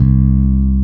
-MM JAZZ C 3.wav